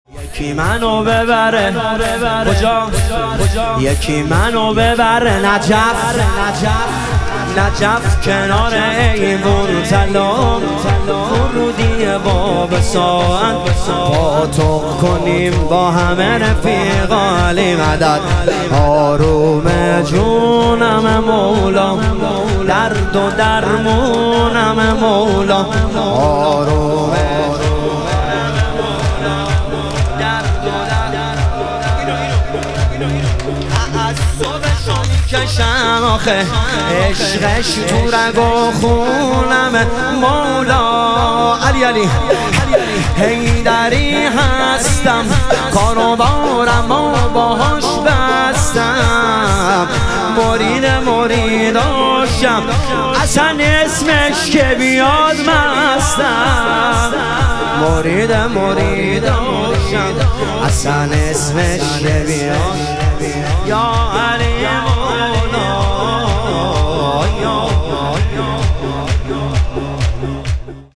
شور
شب شهادت امام هادی علیه السلام